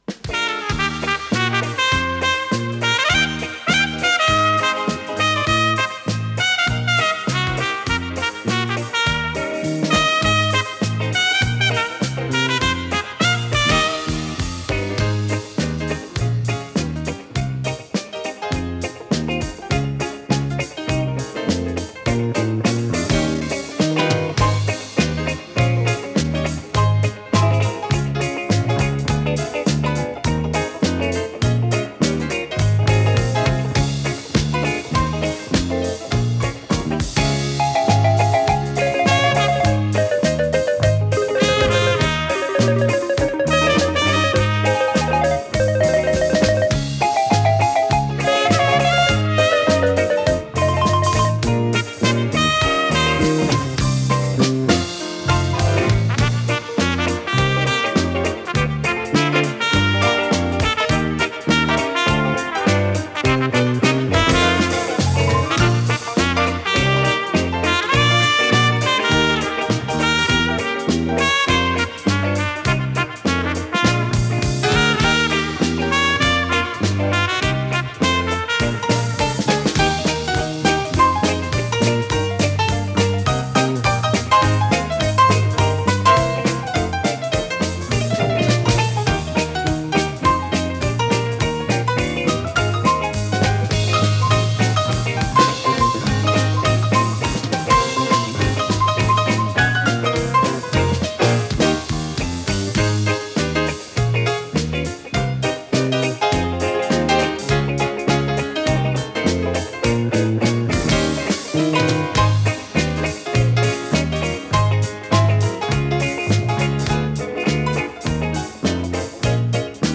с профессиональной магнитной ленты
Скорость ленты38 см/с
ВариантДубль моно